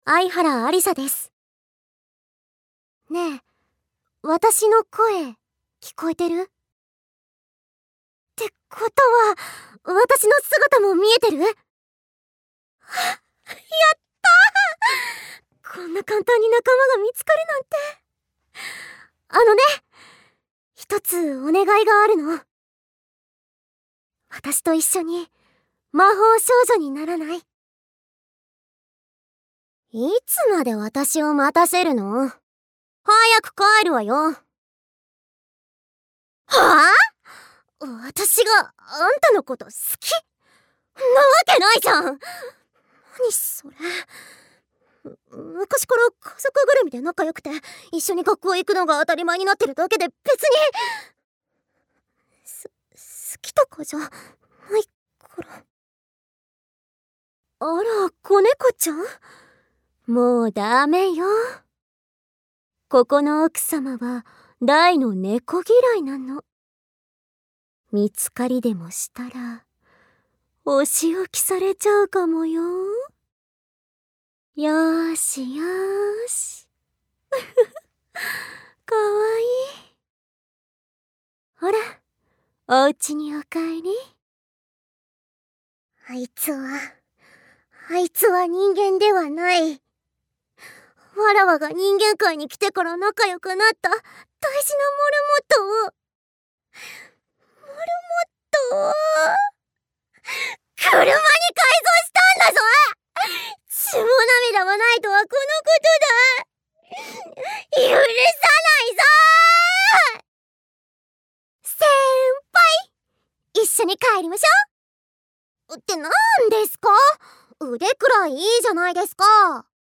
サンプルボイス